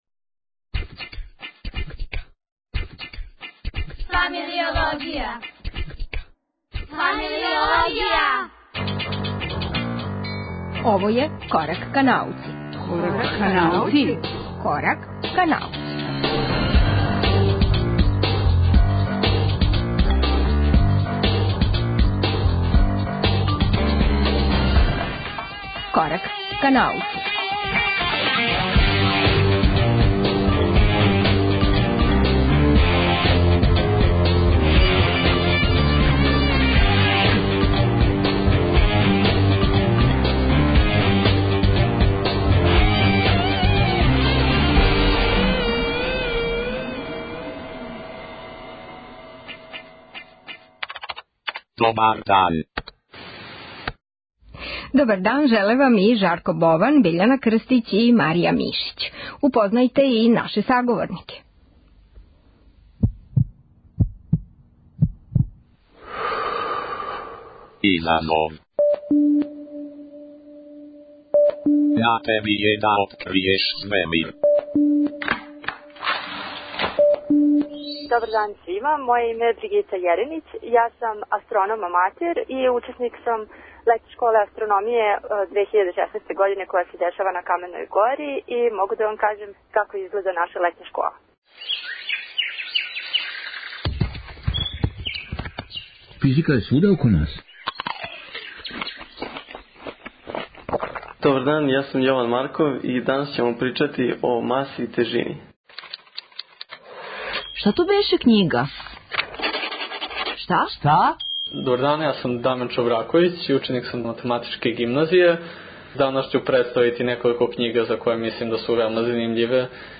Изазови: телефонско укључење са Летње школе астрономије коју АД 'Руђер Бошковић' организује на Каменој гори.